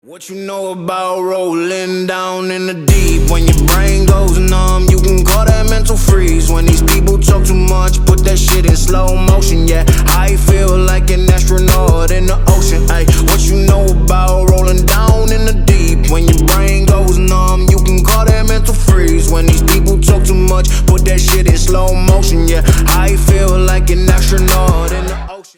Громкие рингтоны
Бас